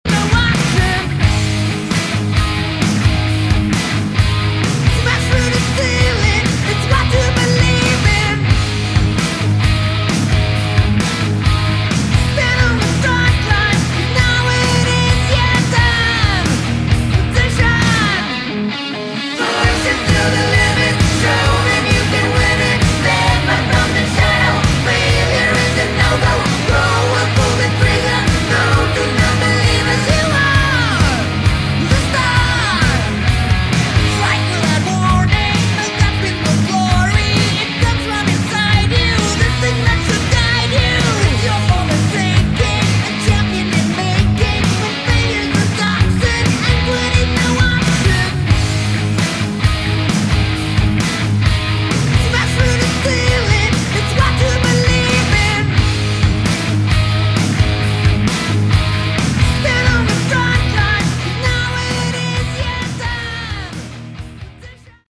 Category: Sleazy Hard Rock
vocals
guitars
drums
bass